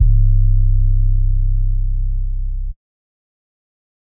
Trap808.wav